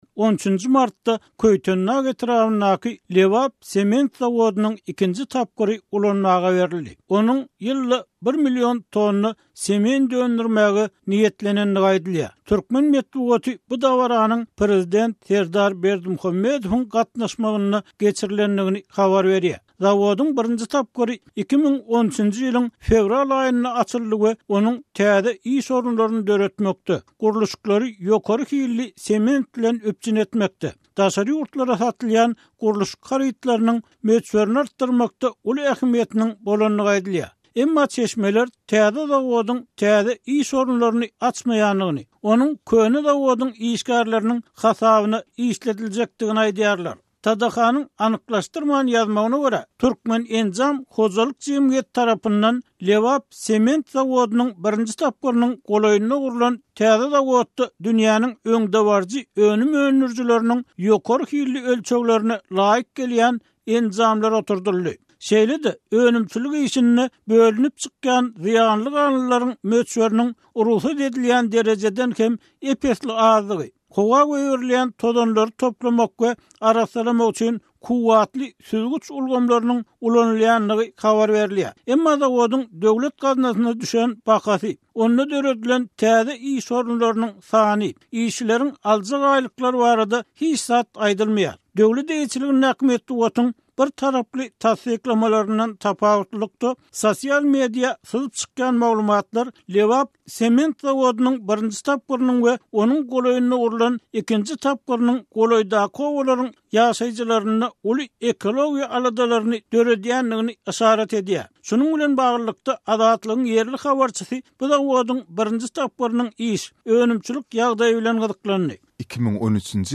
Türkmenistanda gurulýan sementiň hili barada edilmeli çynlakaý aladalar ýene bir gezek 'uludan tutulan aýdym-sazly, alkyşly dabaranyň aşagynda galdy, sement kyssasy özüňi aldamagyň özboluşly nusgasyna öwrüldi' diýip, ýerli hünärmen Azatlyk bilen söhbetdeşlikde aýtdy.